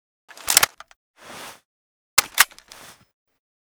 9a91_reload.ogg